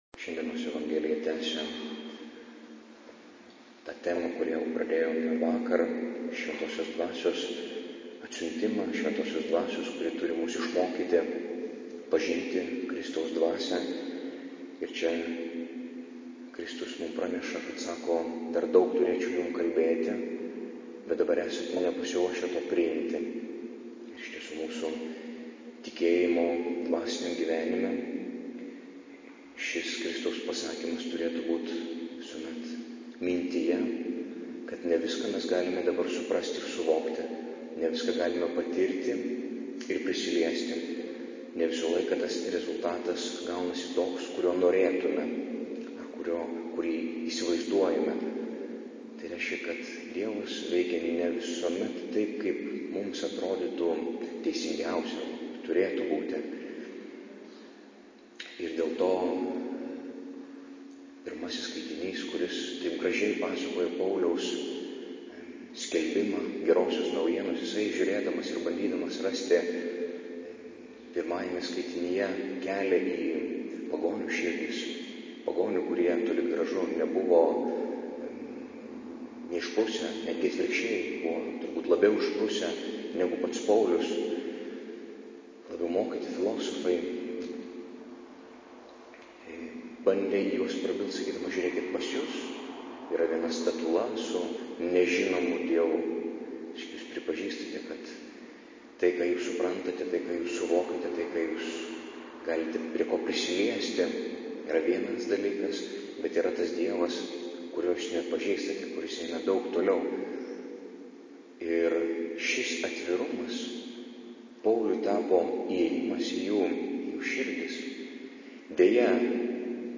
Audio pamokslas: